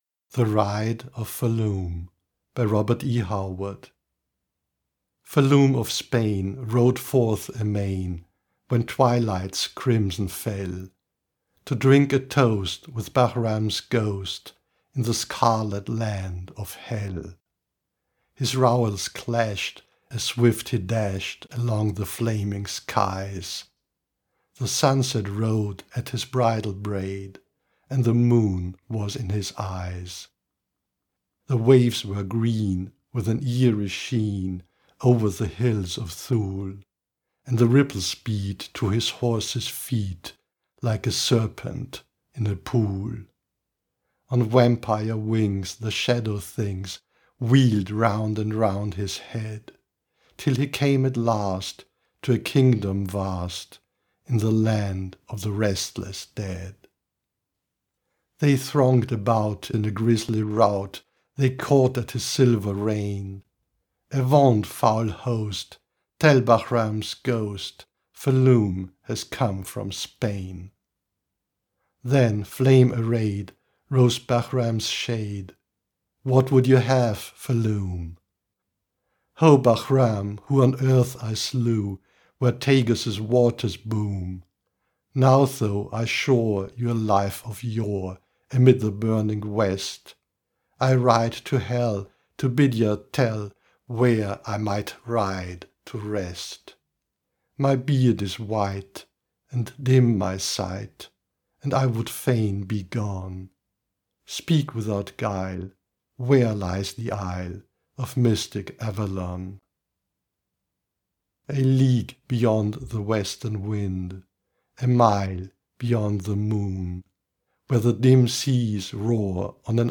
Audio Recordings of Poems by Robert E. Howard